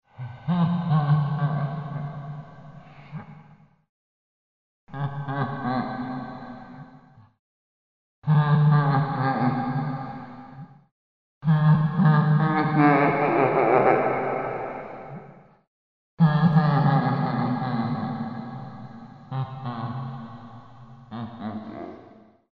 • Категория: Звуки из ада
• Качество: Высокое